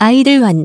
그래서 전문 성우의 목소리로 문장을 읽어주는 TTS 소프트웨어를 찾아봤는데, 마침 250자까지는 공짜(?)로 되는 착한 곳이 있더군요~
따라서 위의 TTS 프로그램으로 소리를 내면서 동시에 GoldWave로 녹음을 하면 되는 것입니다.